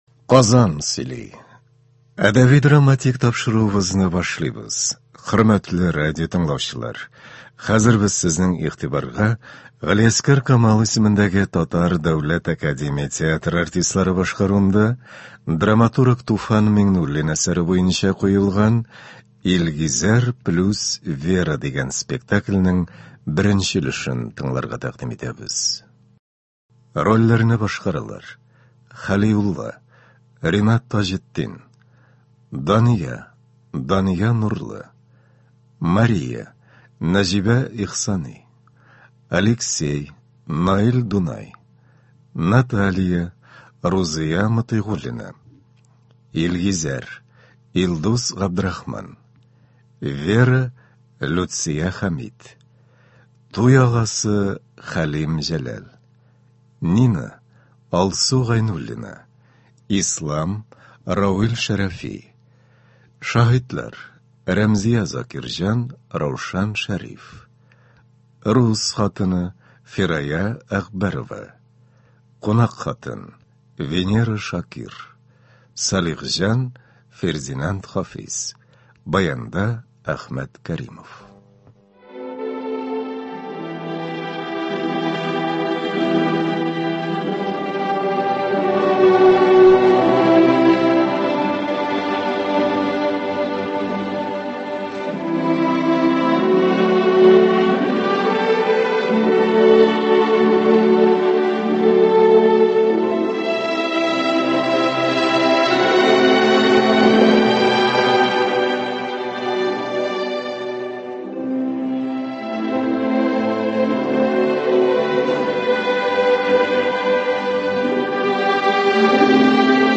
“Илгизәр + Вера”. Г.Камал ис.ТДАТ спектакле. 1 нче өлеш.